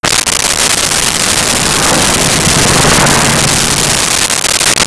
Car Fast By Dry
Car Fast By Dry.wav